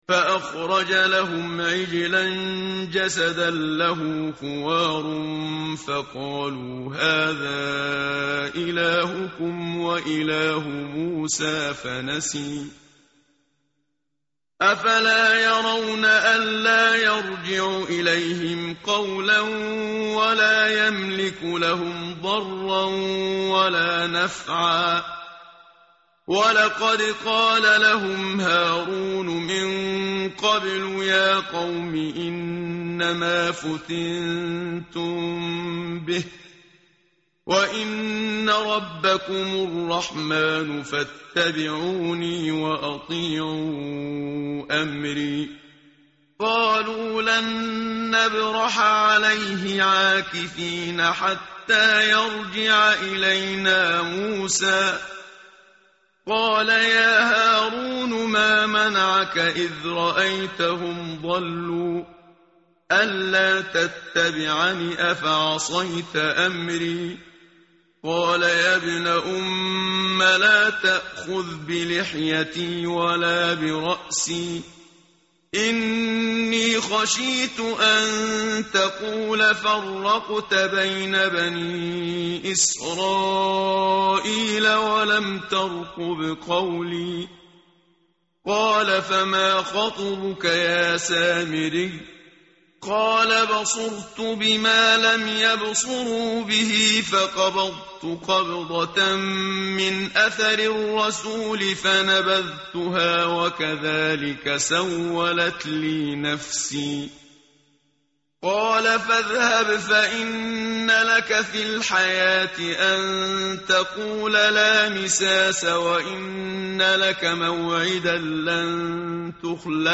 متن قرآن همراه باتلاوت قرآن و ترجمه
tartil_menshavi_page_318.mp3